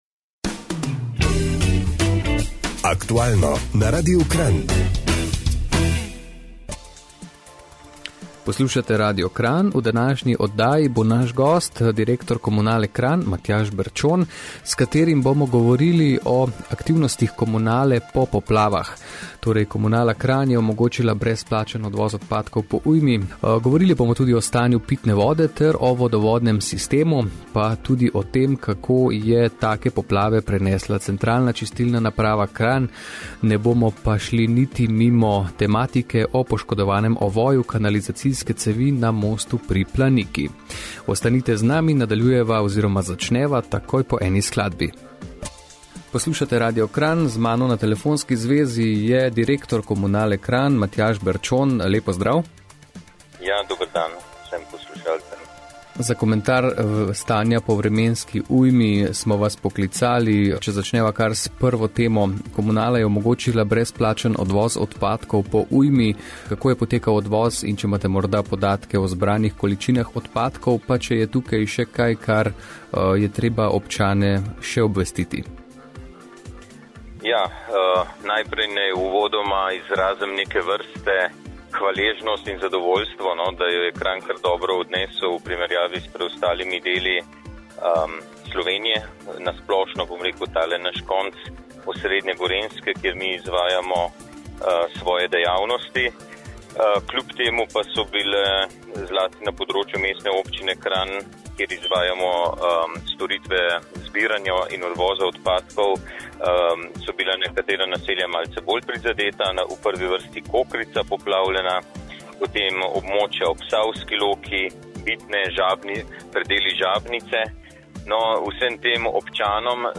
Intervju na Radiu Kranj | Komunala Kranj